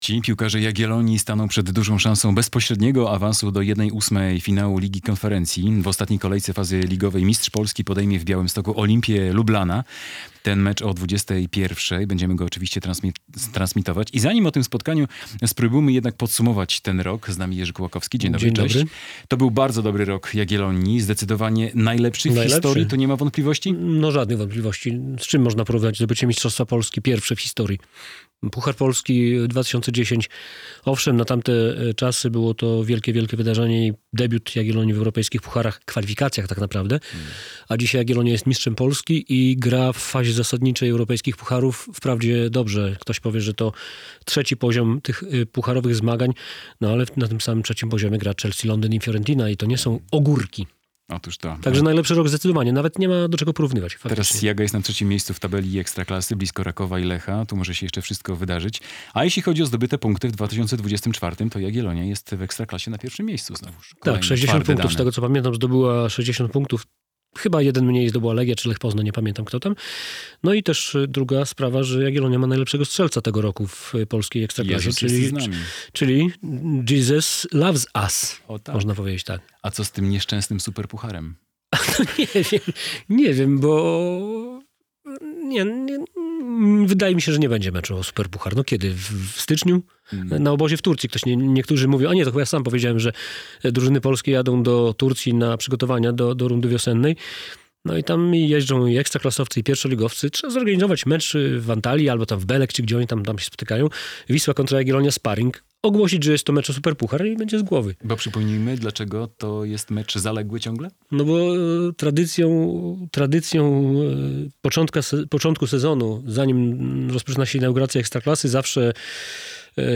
Przed meczem Jagiellonii - relacja